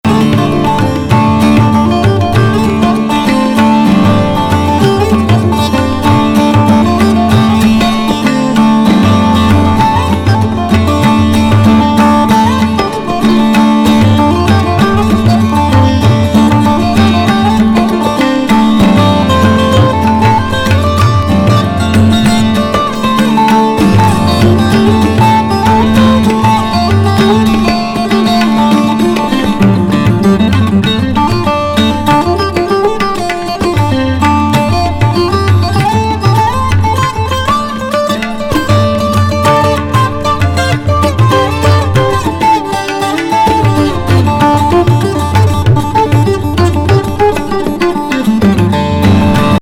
タブラ＋ギターのアラビック